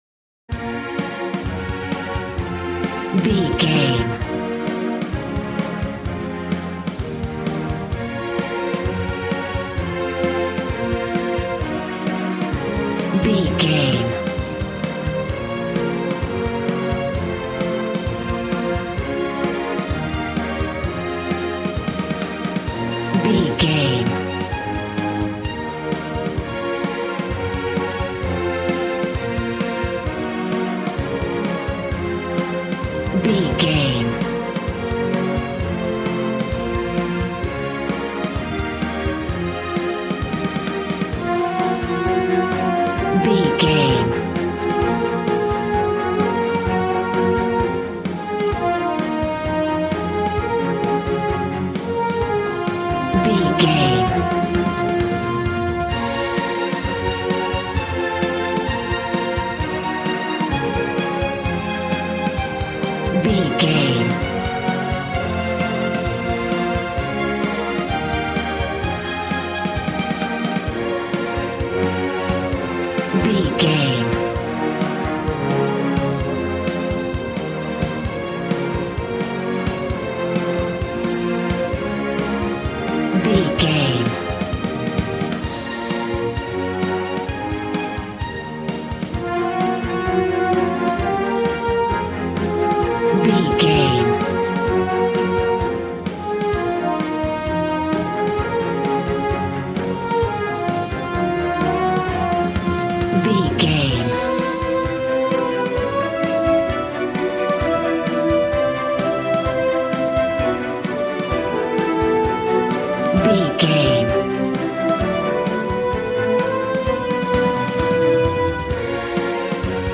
Aeolian/Minor
B♭
dramatic
strings
violin
brass